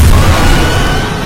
Five Nights at Toy Bonnie's Jumpscare Sound
five-nights-at-toy-bonnies-jumpscare-sound.mp3